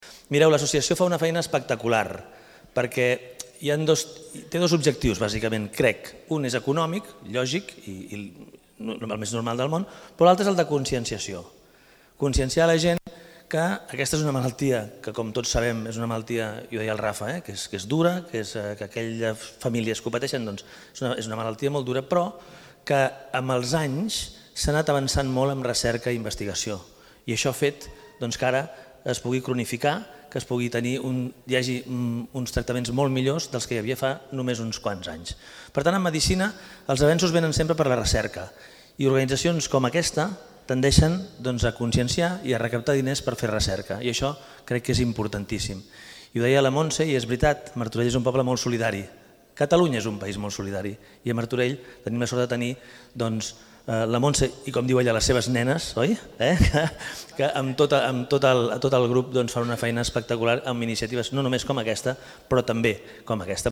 Xavier Fonollosa, alcalde de Martorell